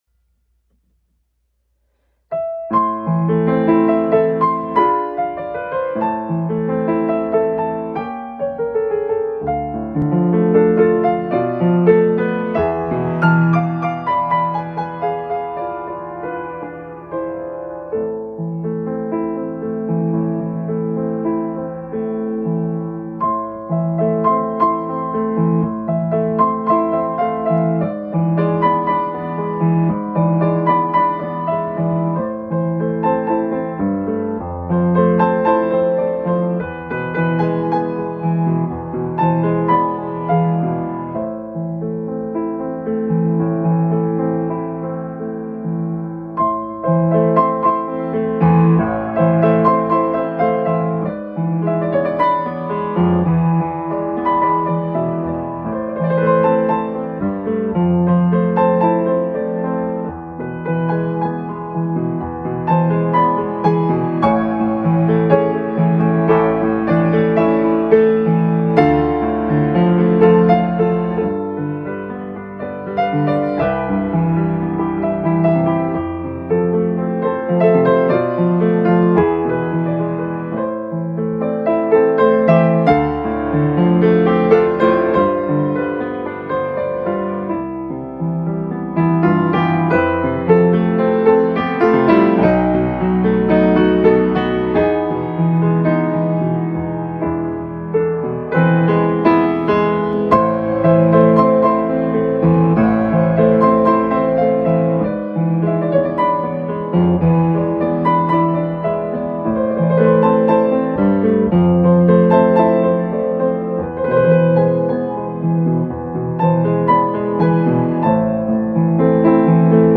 피아노 연주